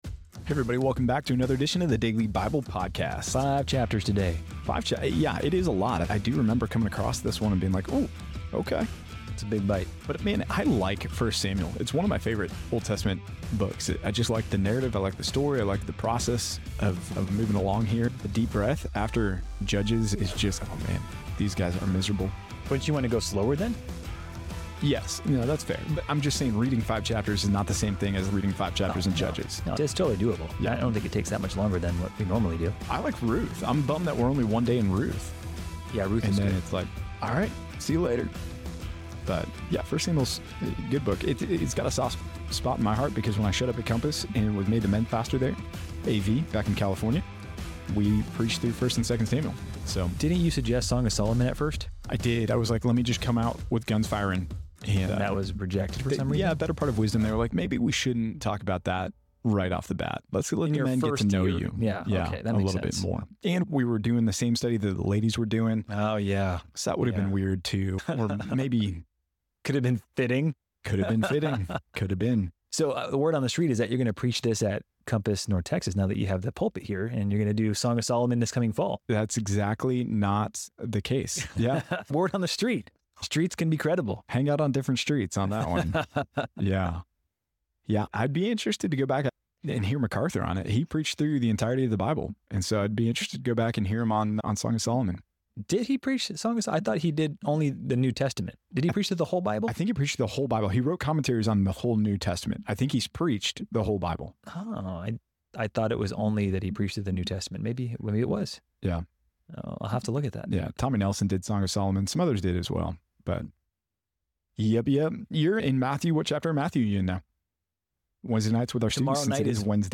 In this episode of The Daily Bible Podcast, the hosts discuss their personal connections to the book of 1 Samuel and its narrative. They cover chapters 4-8, highlighting Israel's battle failures due to misplaced trust in the Ark instead of God, the plagues faced by the Philistines for capturing the Ark, and the Ark's eventual return to Israel.
The episode concludes with a prayer for living distinct, godly lives in today's world.